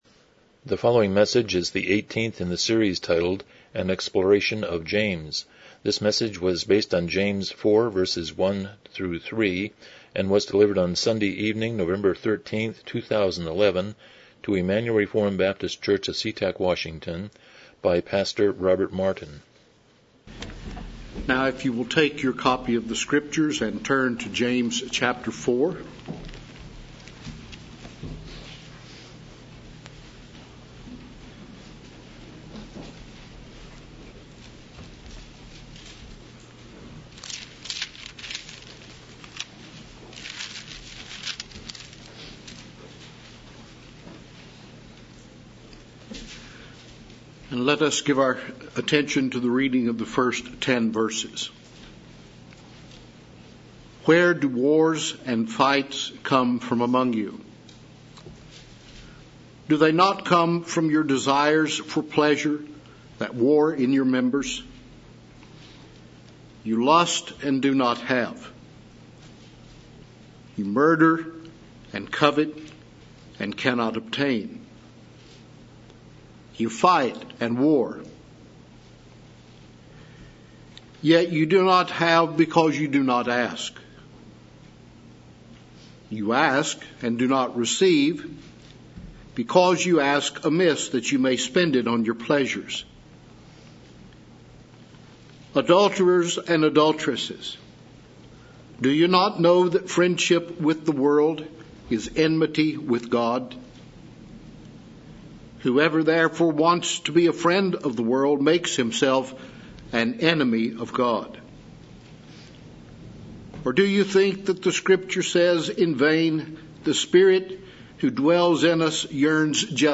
James 4:1-3 Service Type: Evening Worship « 155 Romans 14:20-15:7 134 Chapter 26.15